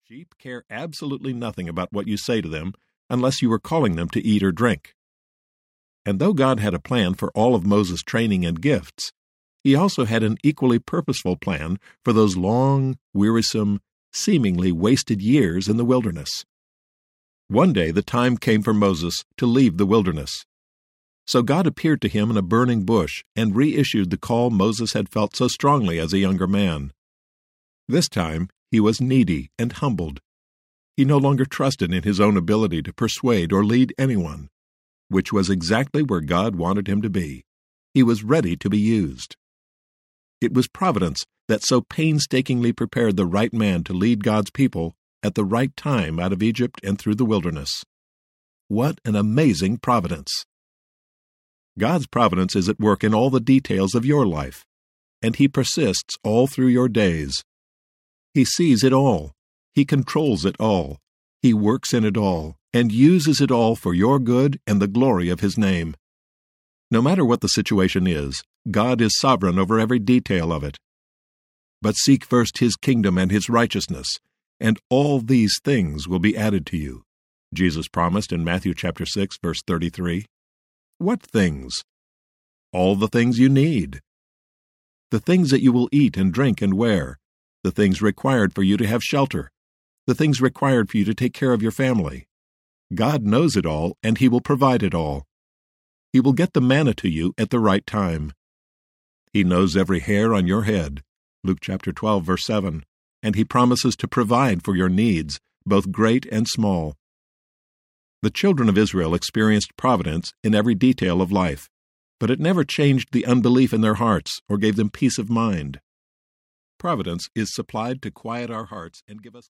Manna Audiobook